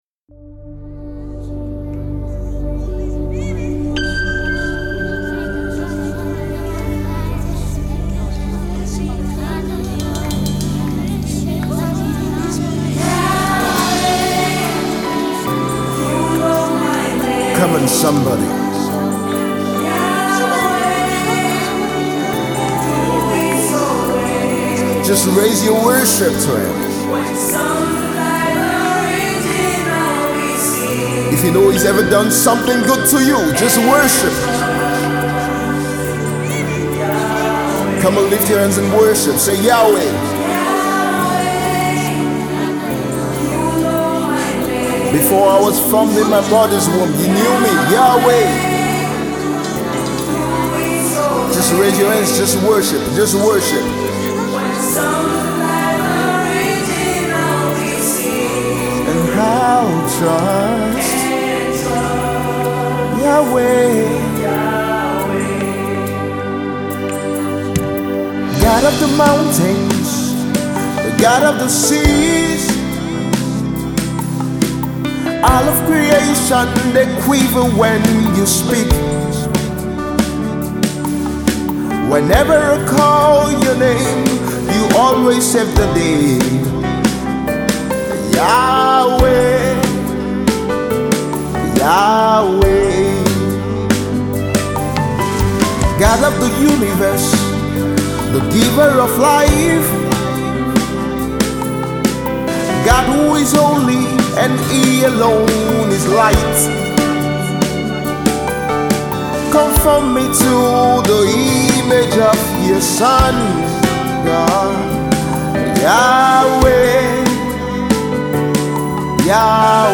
gospel sensation
spiritual song